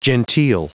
Prononciation du mot genteel en anglais (fichier audio)
Prononciation du mot : genteel